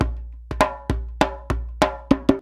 100DJEMB32.wav